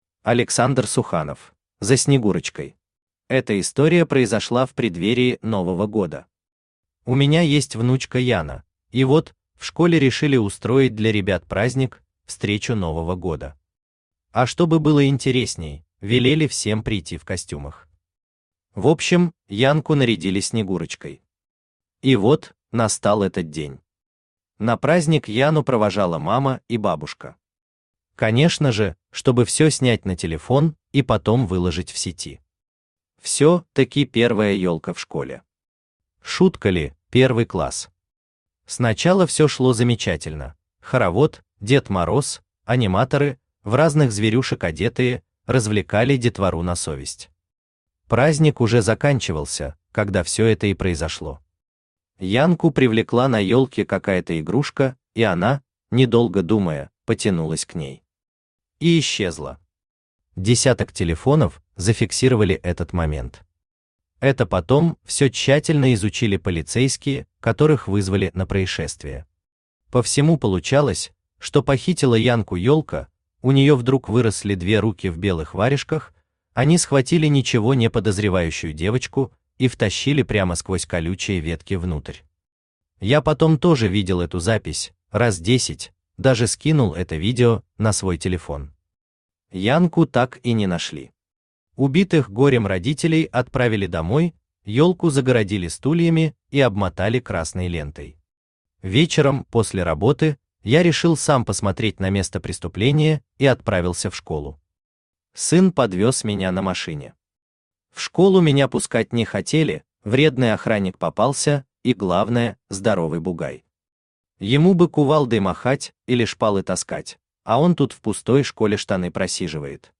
Aудиокнига За Снегурочкой Автор Александр Суханов Читает аудиокнигу Авточтец ЛитРес.